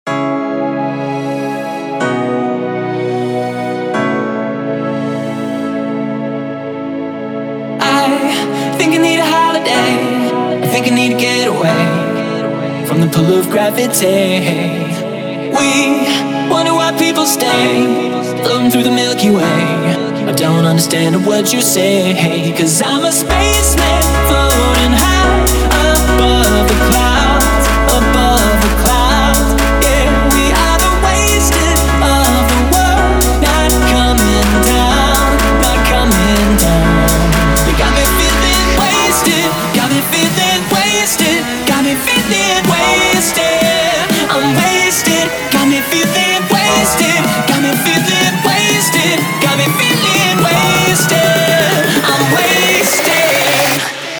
• Качество: 320, Stereo
красивые
спокойные
вокал
мужской